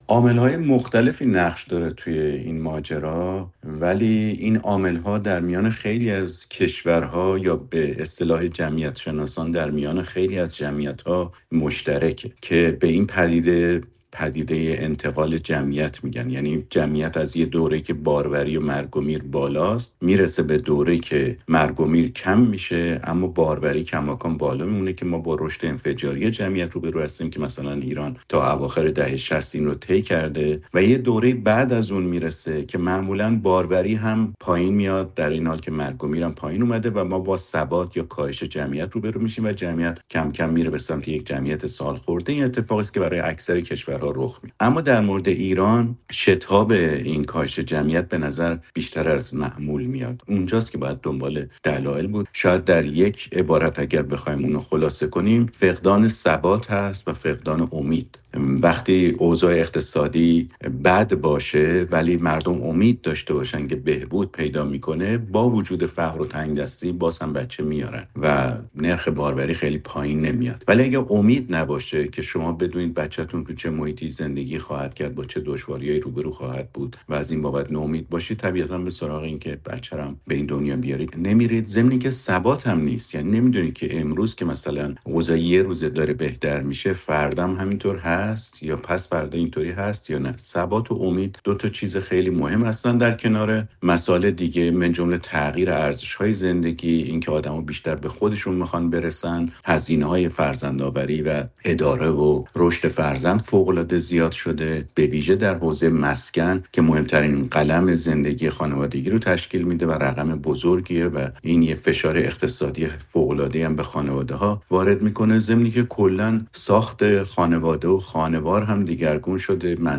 دلایل کاهش تمایل به فرزندآوری در ایران؛ گفت‌وگو